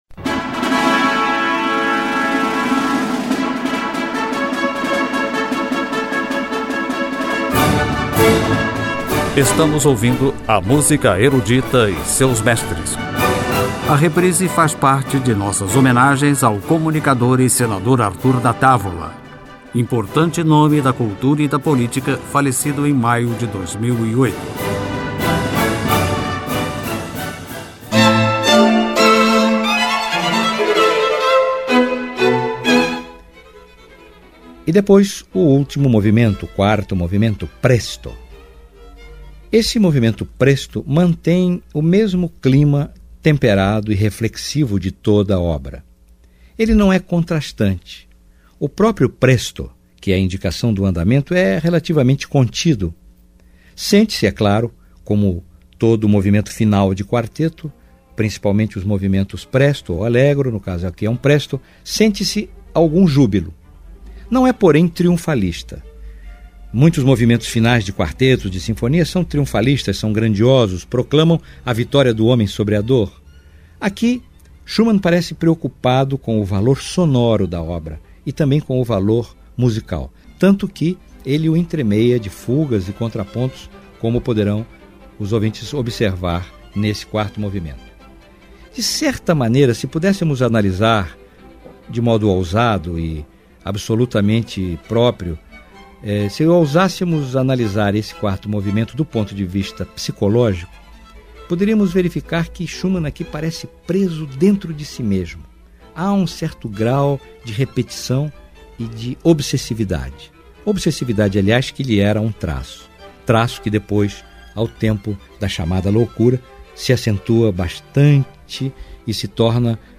Quarteto para Cordas em Lá Menor
Fantasias pra Clarinete e Piano
ao clarinete
com o barítono Dietrich Fischer Dieskau